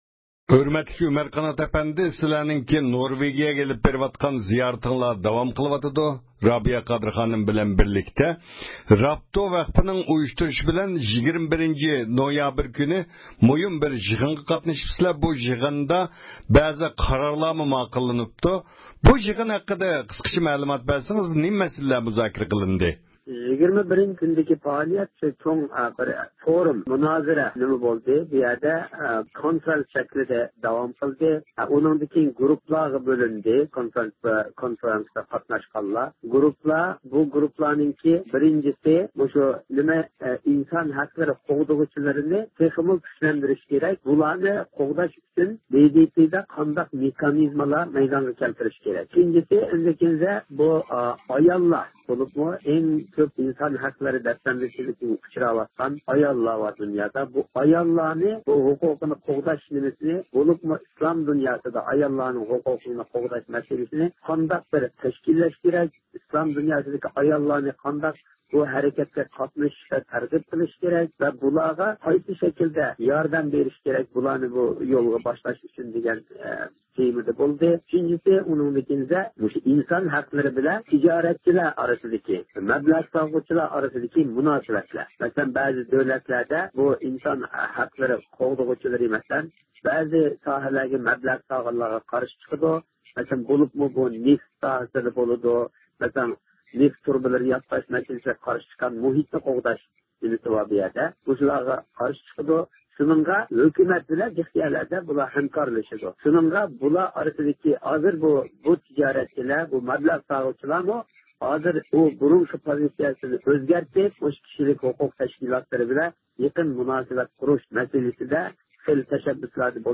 مۇخبىرىمىزنىڭ سوئاللىرىغا جاۋاب بەردى.